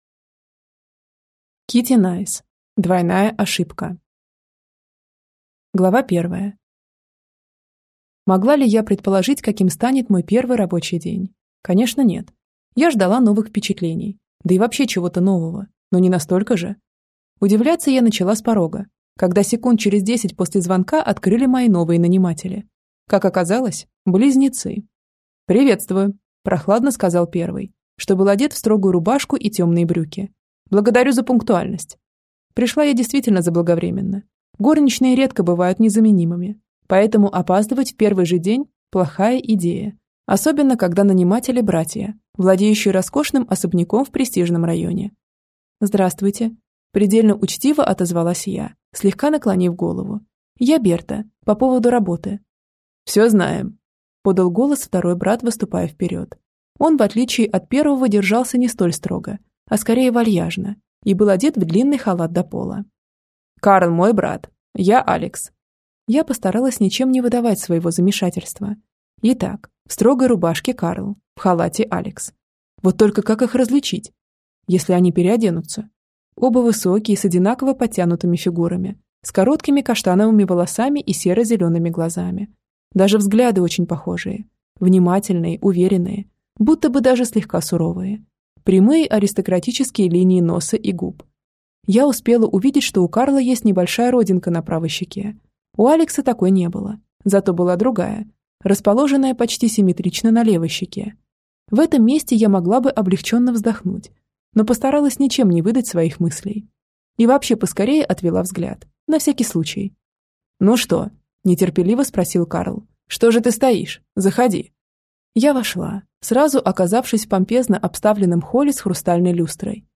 Аудиокнига Двойная ошибка | Библиотека аудиокниг